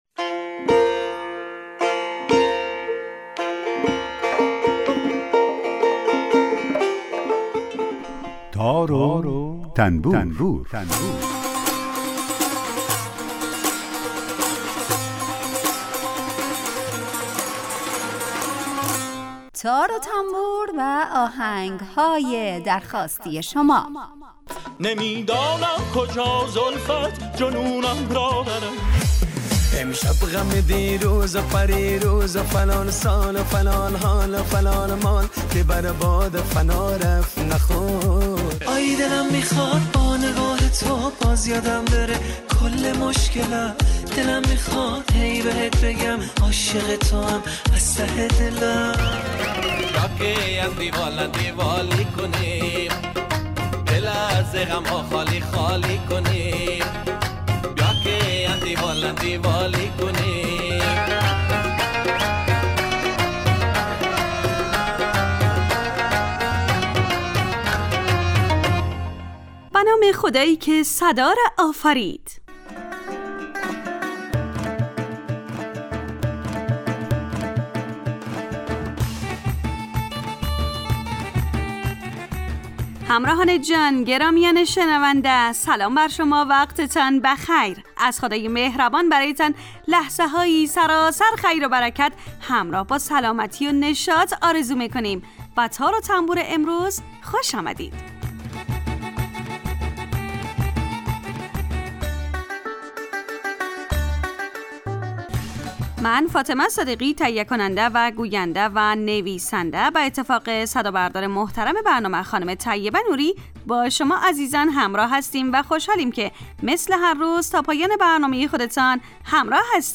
آهنگهای درخواستی شما